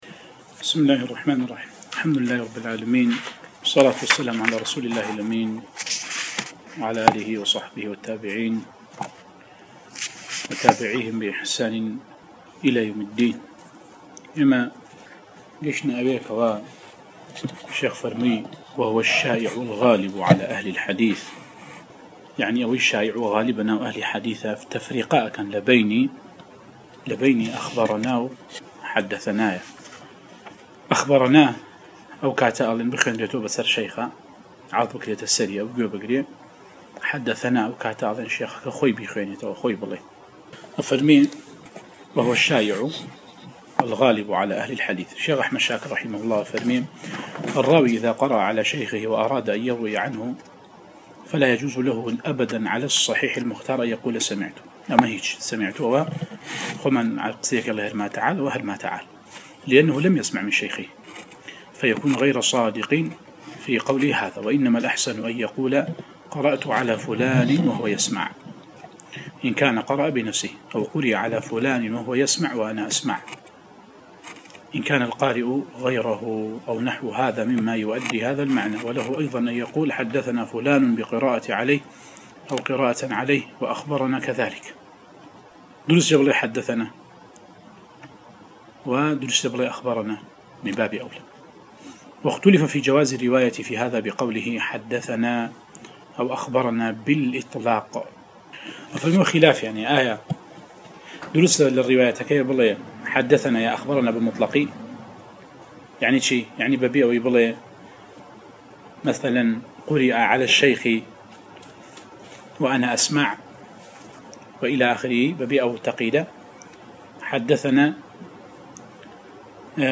القراءة والتعليق على مواضع من الباعث الحثيث ـ 16